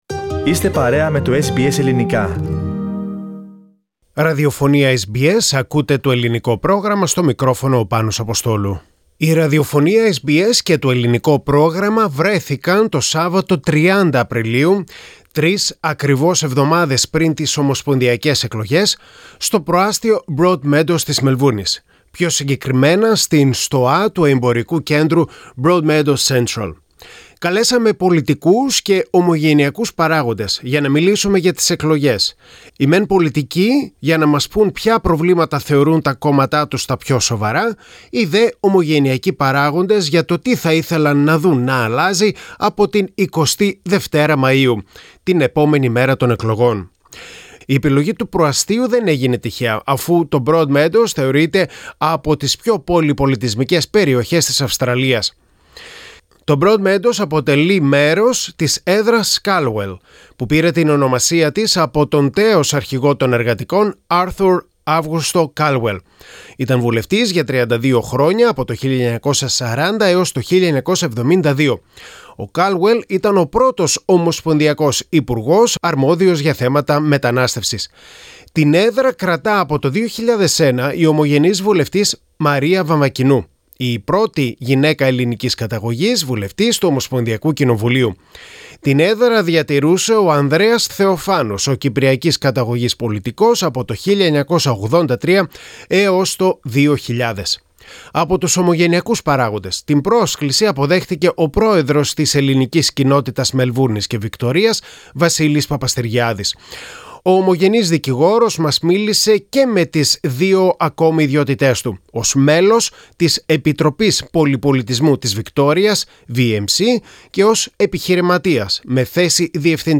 'What are you asking from the next Federal Government?' Community leaders and politicians in an SBS Radio election event
In the lead-up to the Federal Election election, SBS Radio & SBS Greek are aiming to bring the election to a local level for our audiences and help them make sense of the policies, in an independent and informed way. SBS Greek invited politicians and well-known Greek community leaders there.